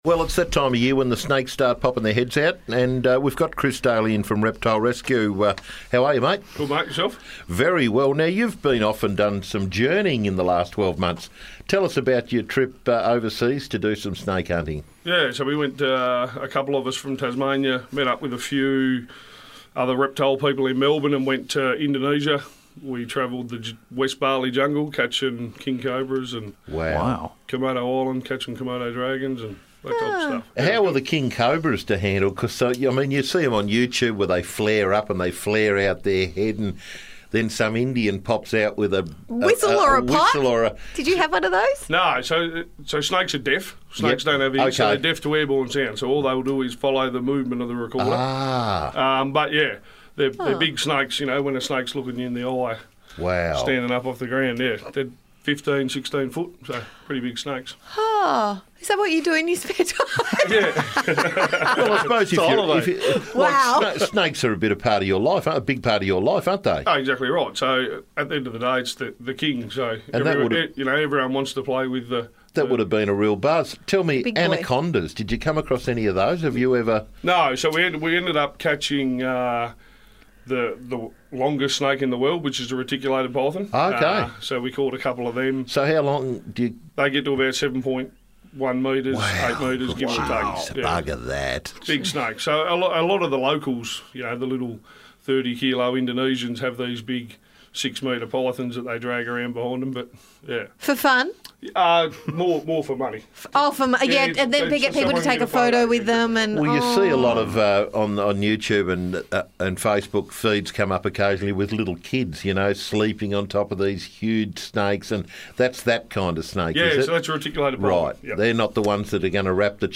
Snakes LIVE in the studio